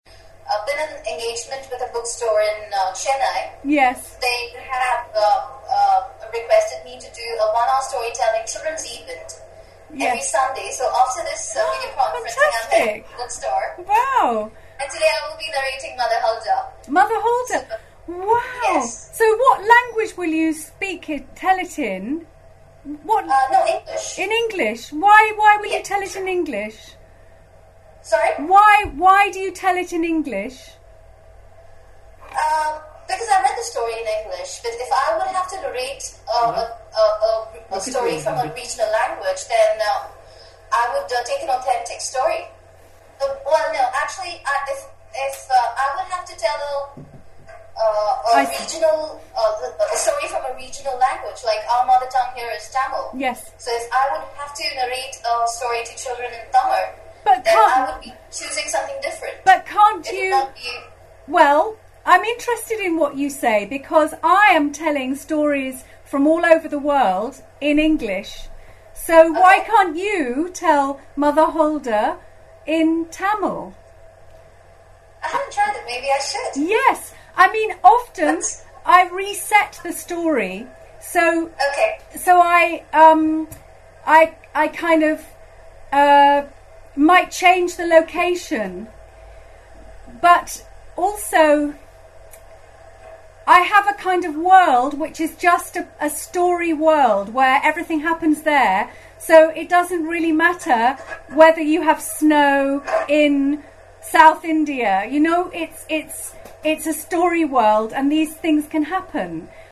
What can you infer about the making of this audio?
video conferencing with India